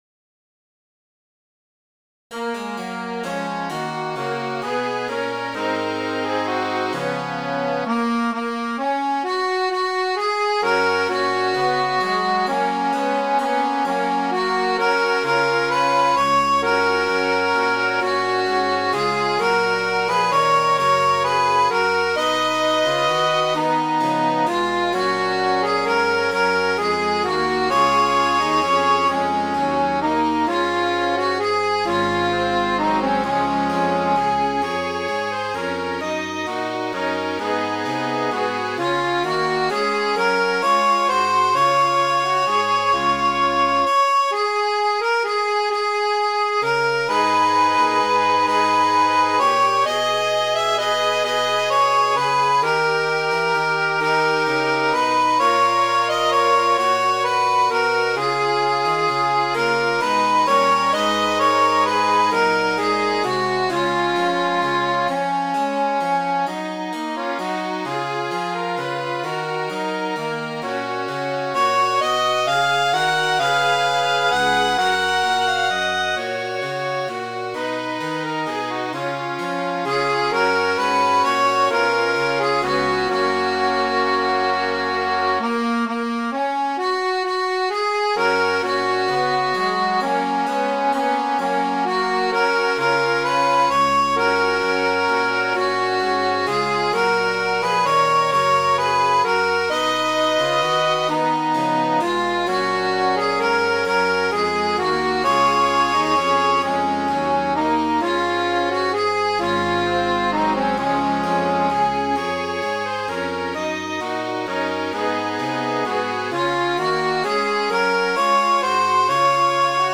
Midi File, Lyrics and Information to A Fox May Steal Your Hens, Sir